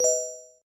GemCollected.ogg